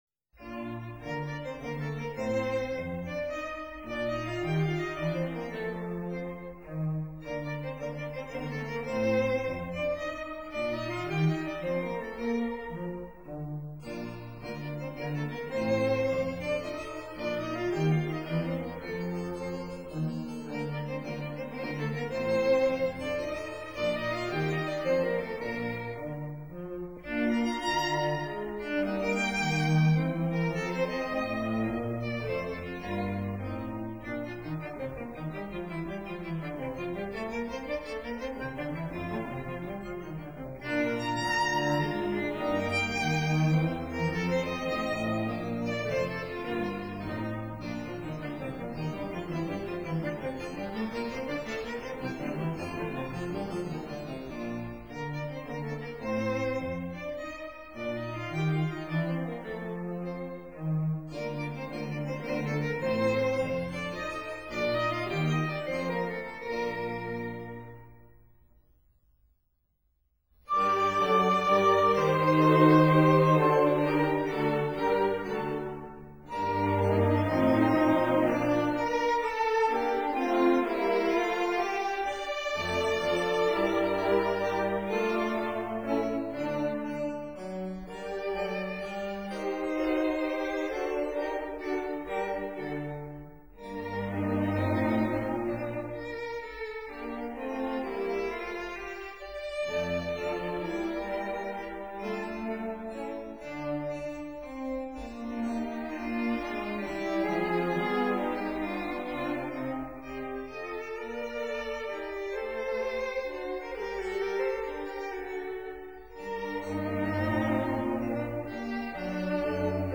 ("Old School" Period Instruments)